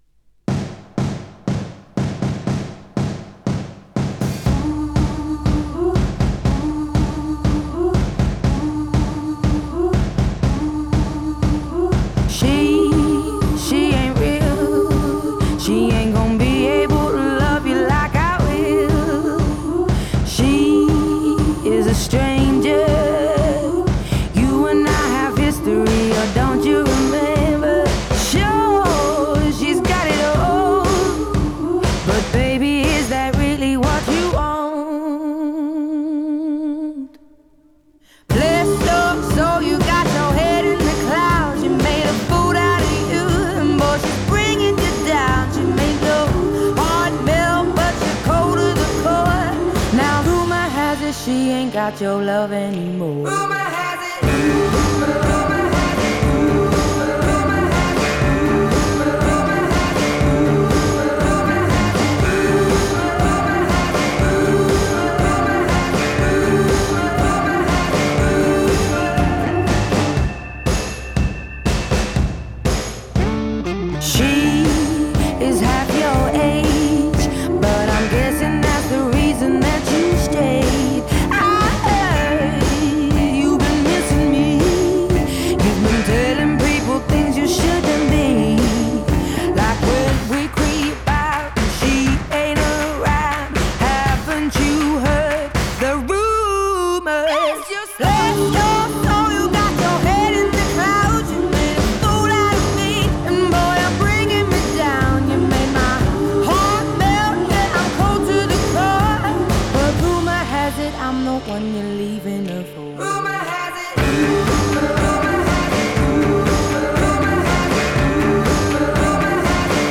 Genre: Pop, Blues